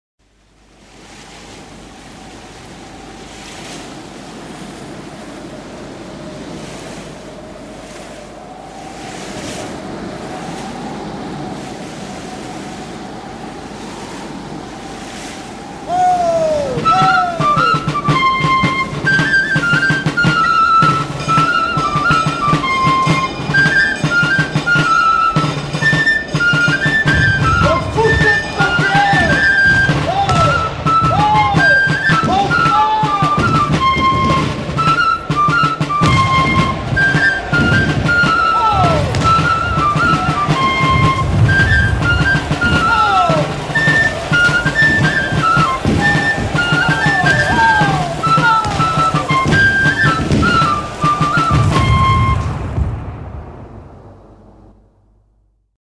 Editeur Note le fifre tient plusieurs rôles.
Pièce musicale éditée